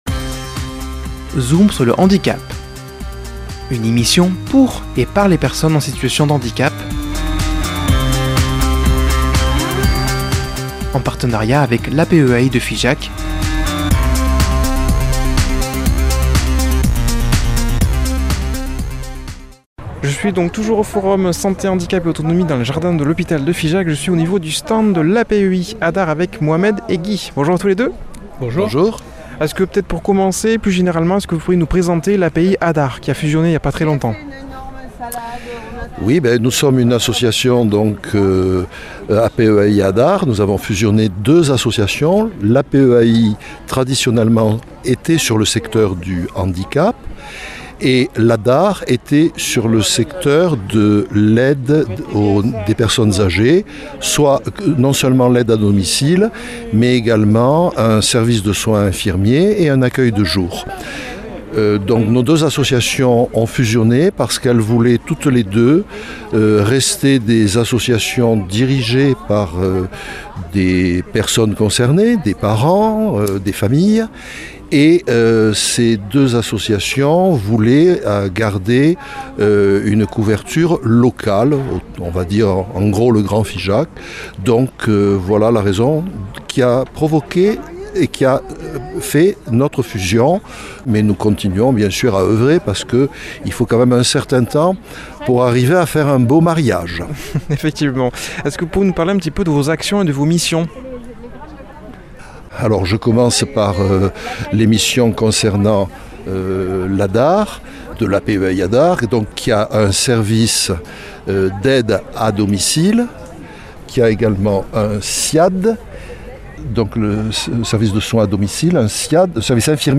Un reportage en plusieurs parties tout au long de cette saison radiophonique. Aujourd’hui, avant dernier épisode, le numéro 8 avec le stand de l’APEAI ADAR